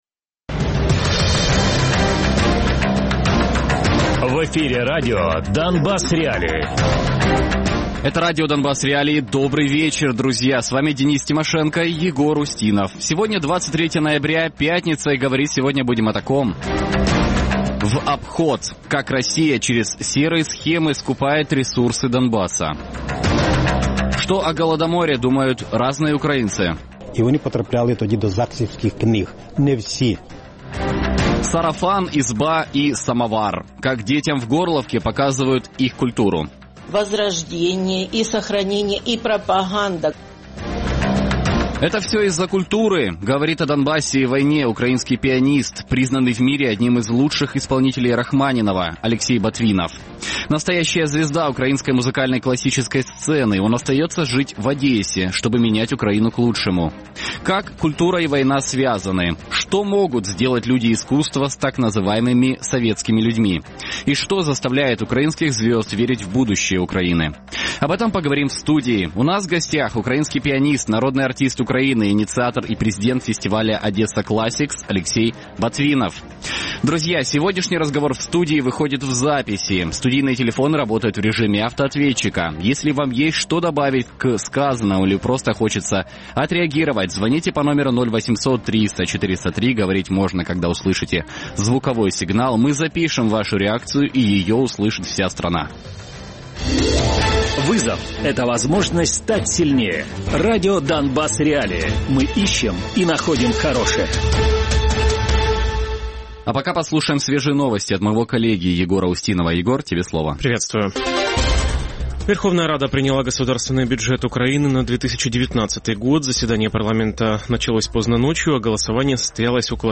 Гість: Олексій Ботвінов, український піаніст, народний артист України, президент фестивалю «Odessa Classics» Радіопрограма «Донбас.Реалії» - у будні з 17:00 до 18:00. Без агресії і перебільшення. 60 хвилин про найважливіше для Донецької і Луганської областей.